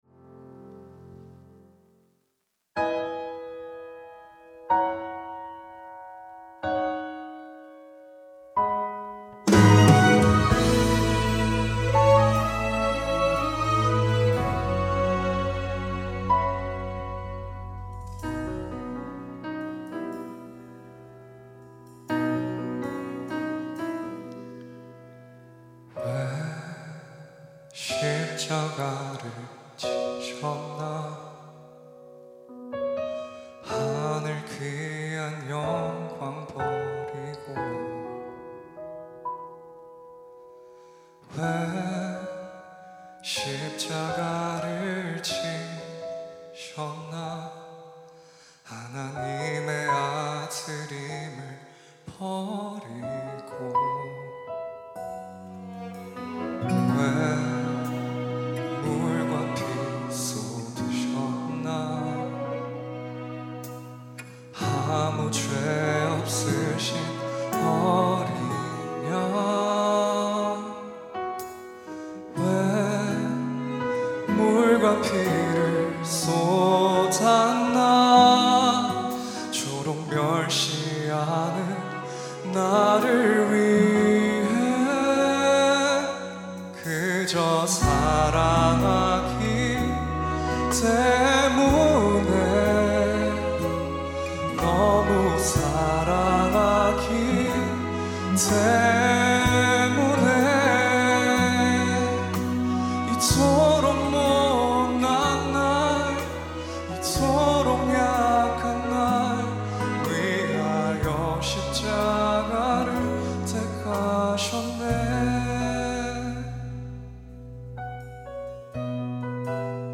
특송과 특주 - 그저 사랑하기 때문에